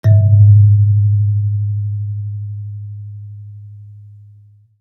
kalimba_bass-G#1-ff.wav